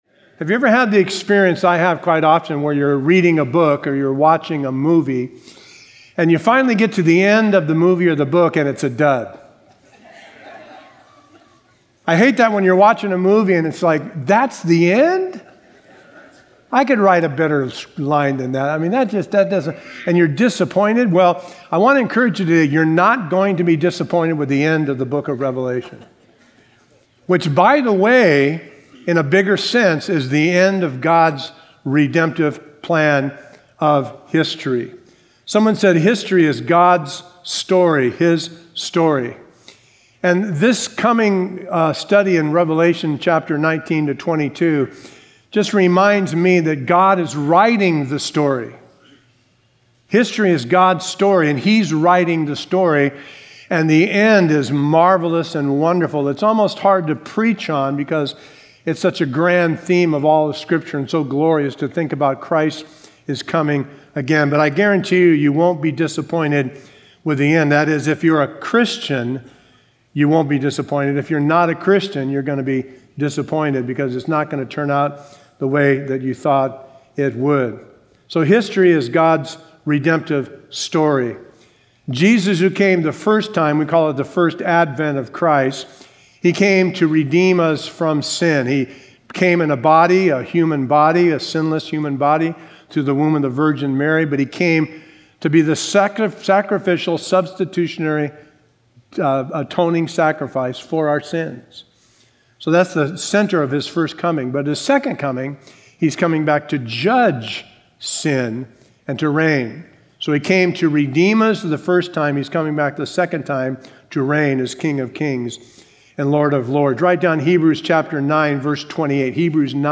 A verse-by-verse expository sermon through Revelation 19:1-16